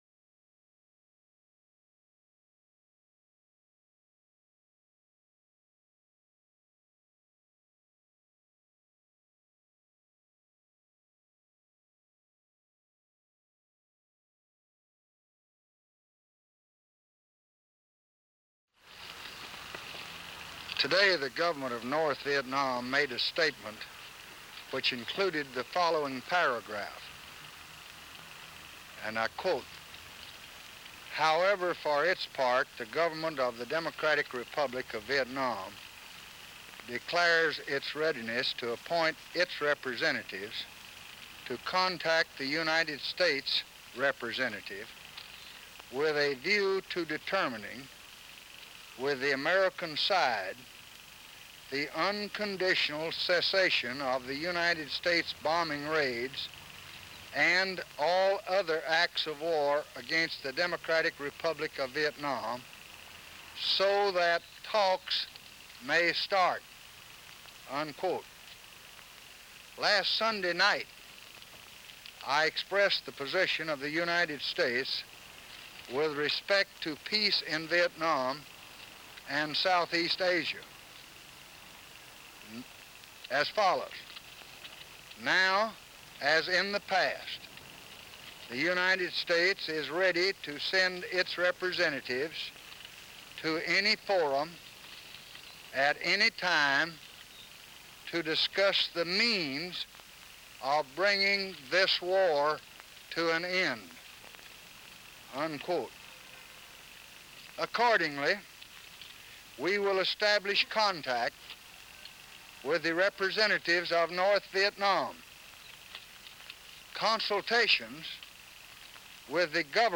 April 3, 1968: Press Conference
President Johnson makes some brief remarks prior to his trip to Honolulu. He addresses a message from the North Vietnamese government which announces their intention to initiate talks.